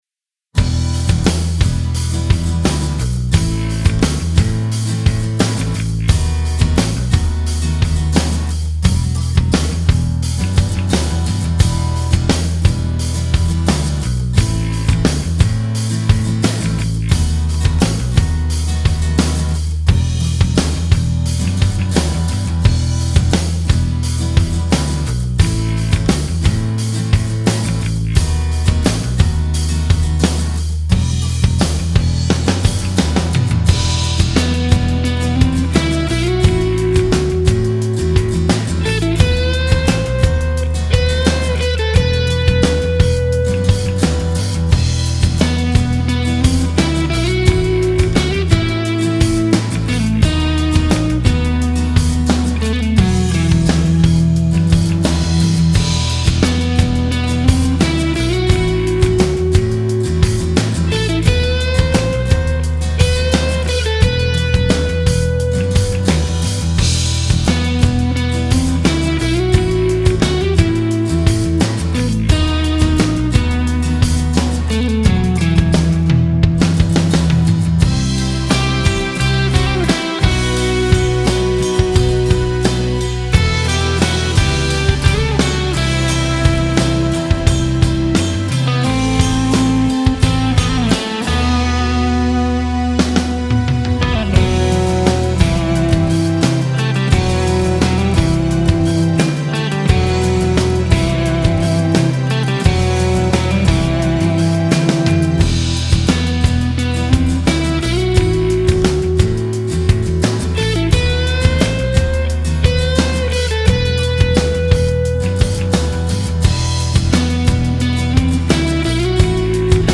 терция в конце сыграна криво и ужасно
семлерный бас - ровная линия